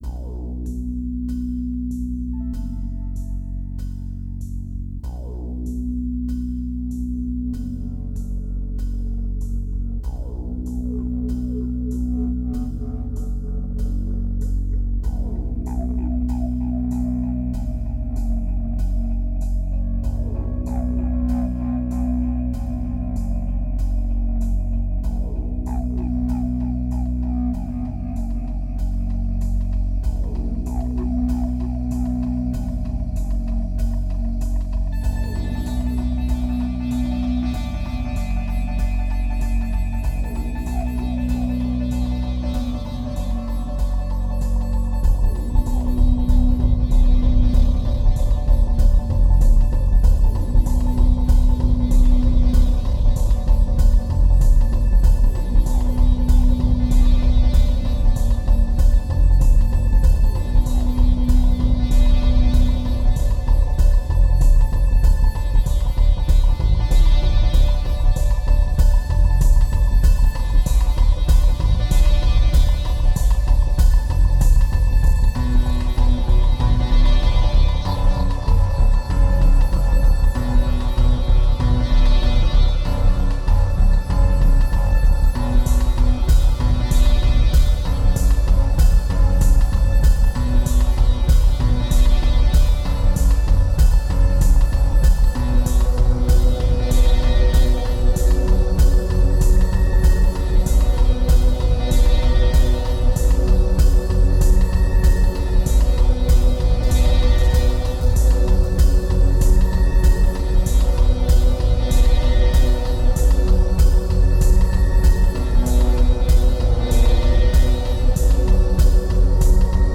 2349📈 - 0%🤔 - 96BPM🔊 - 2010-11-25📅 - -178🌟